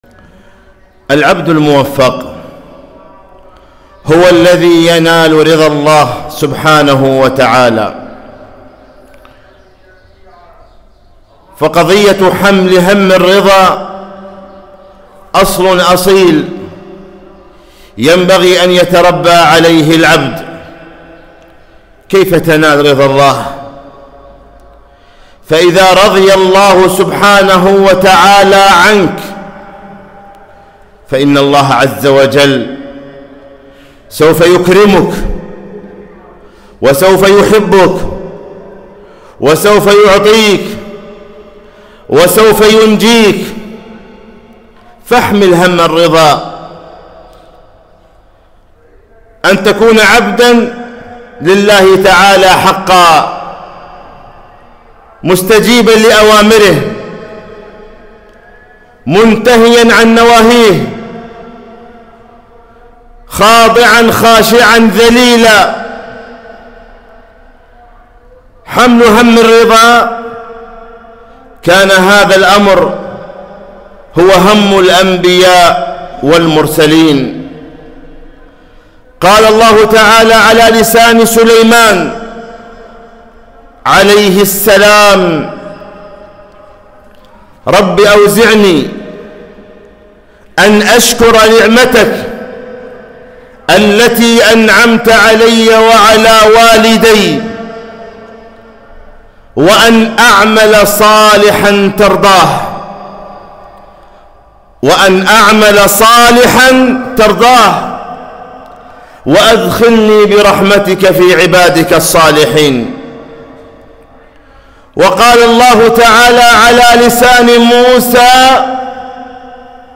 خطبة - كيف تنال رضا الله؟ 3-5-1442